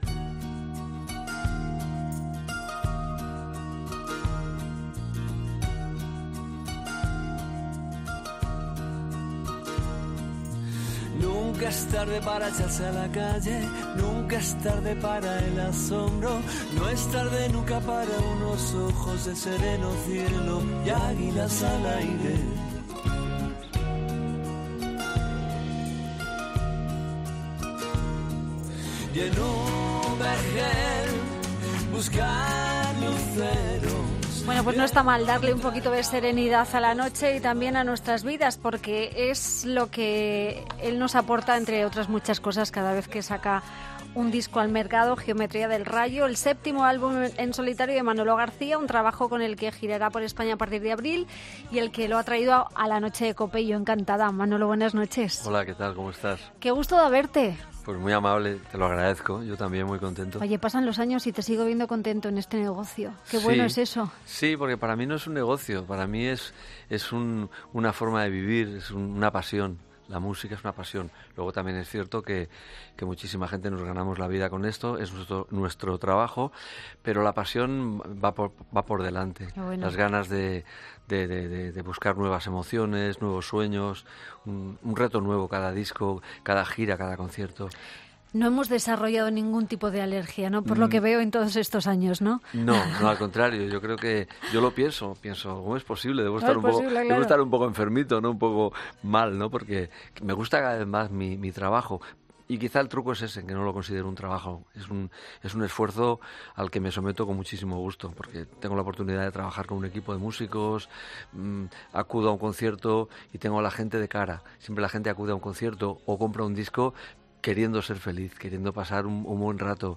Escucha la entrevista a Manolo García en 'La Noche de COPE con Rosa Rosado'